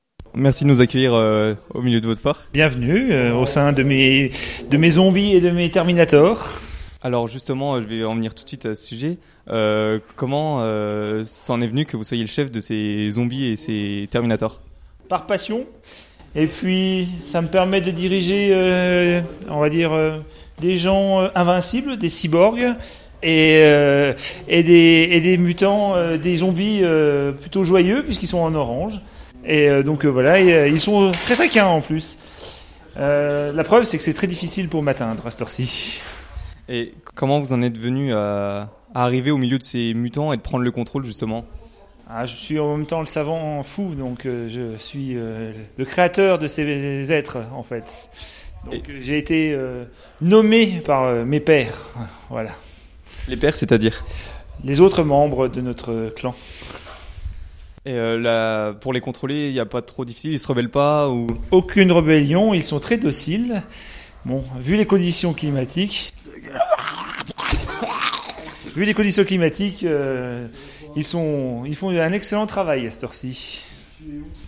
itw-chef-mutant.mp3